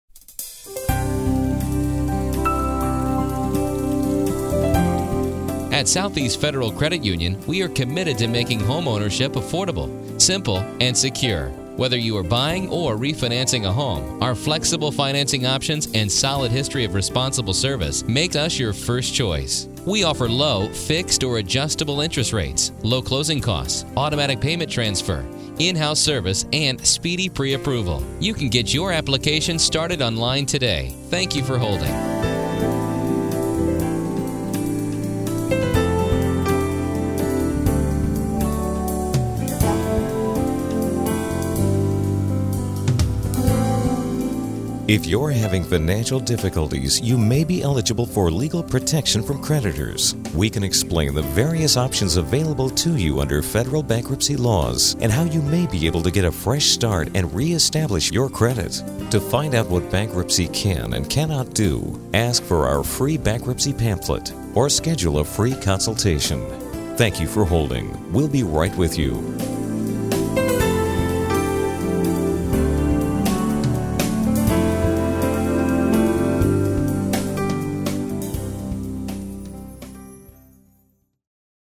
Messages on hold
Music on hold